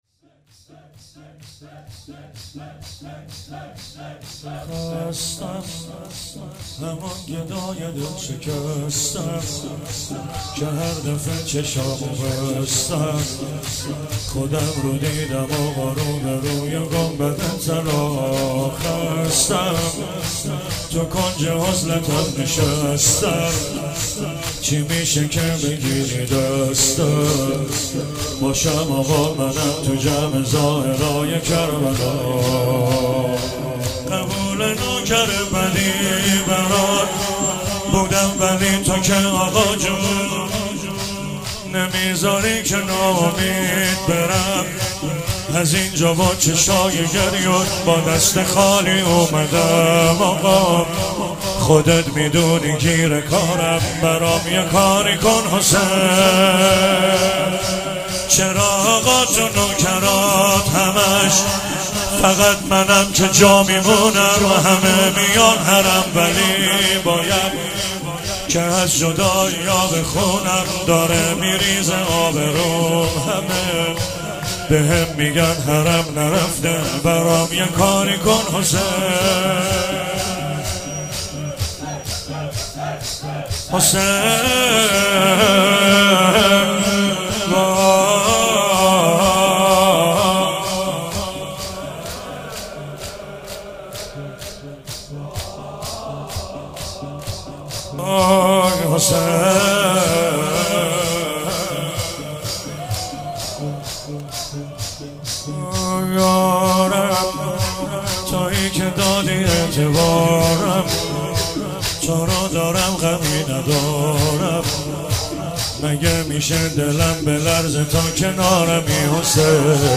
فاطمیه95 - شور - خستم همون گدای دل شکسته ام